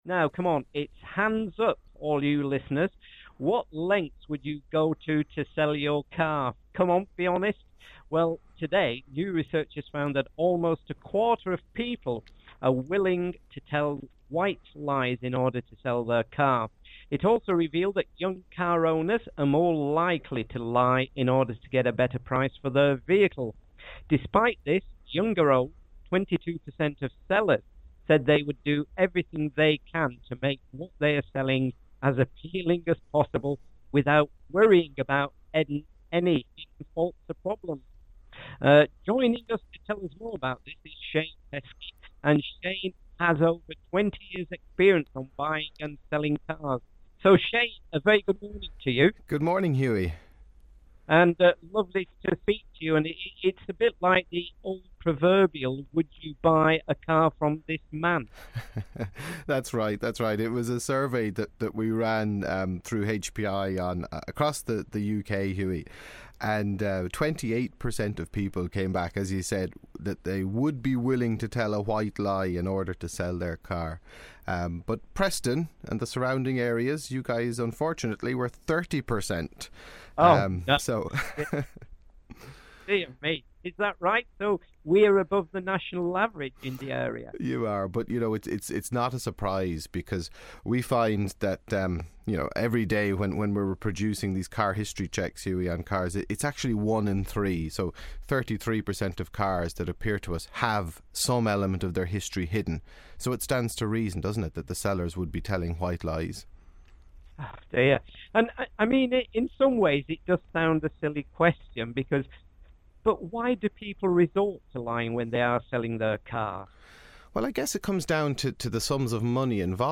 Interview with Preston FM